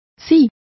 Complete with pronunciation of the translation of whether.